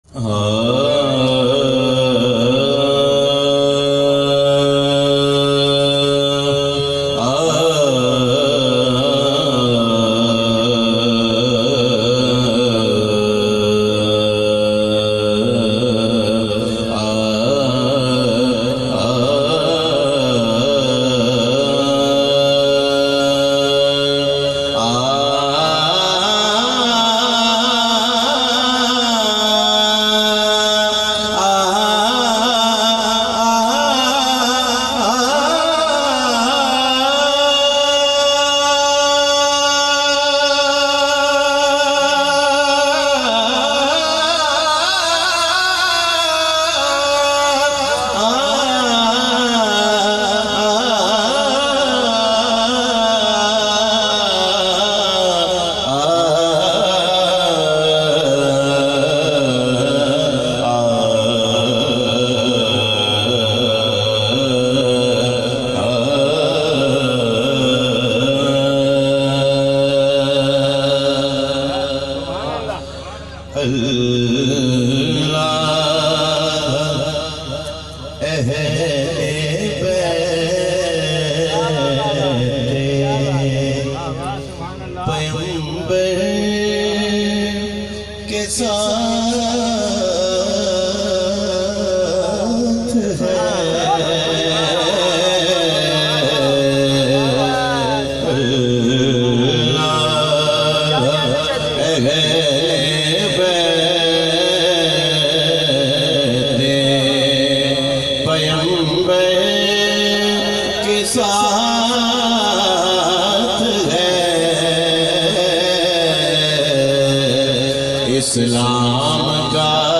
Naat MP3